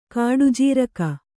♪ kāḍu jīraka